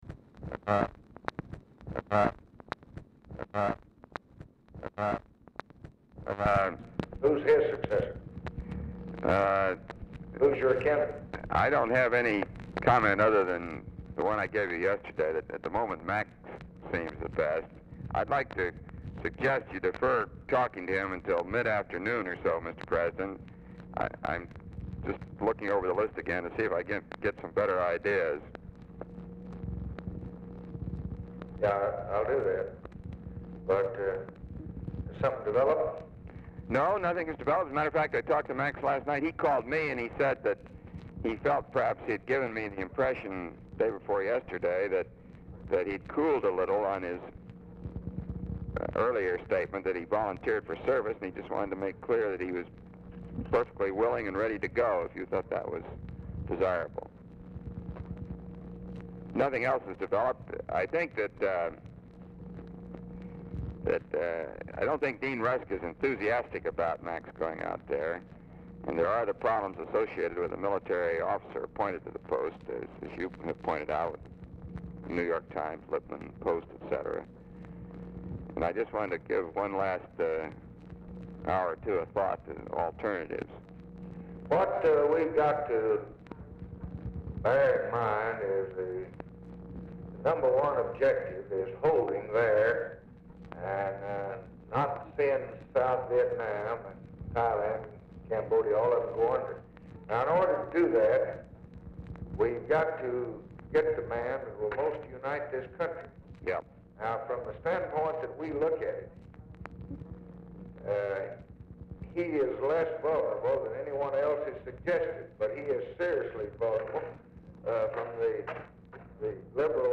LBJ IS ON SPEAKERPHONE
Format Dictation belt
Specific Item Type Telephone conversation